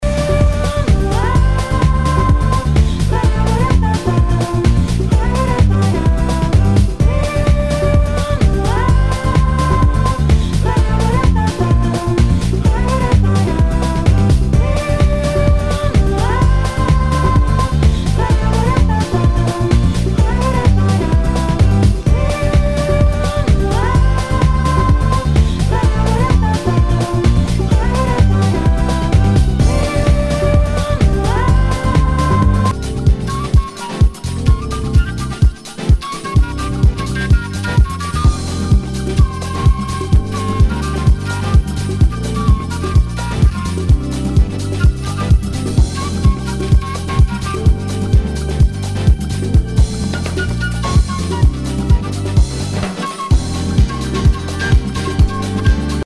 HOUSE/TECHNO/ELECTRO
ナイス！ファンキー・ラテン・ハウス！！